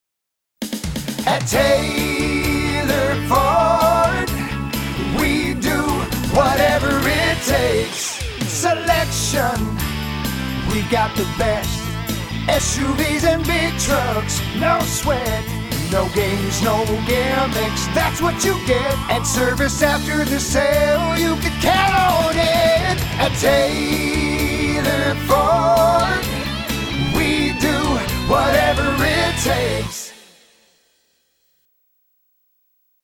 National quality jingles at competitive prices!